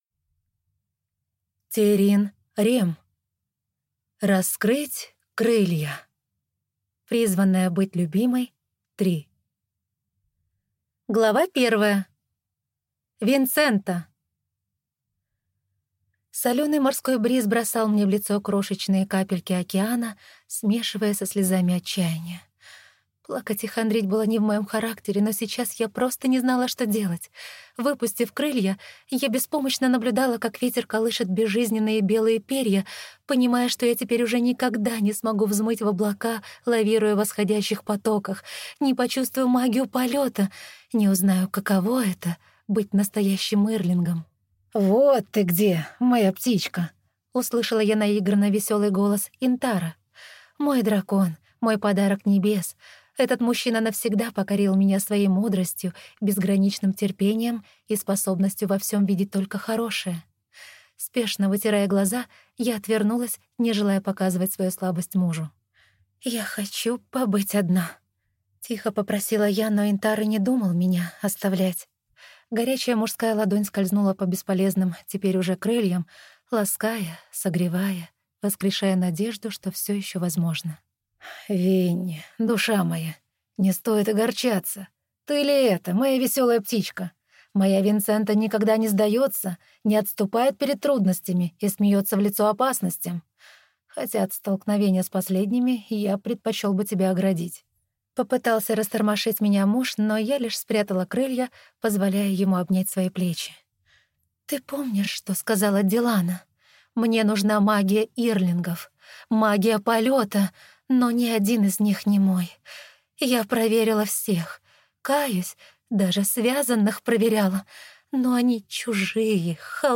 Аудиокнига Раскрыть крылья. Призванная быть любимой-3 | Библиотека аудиокниг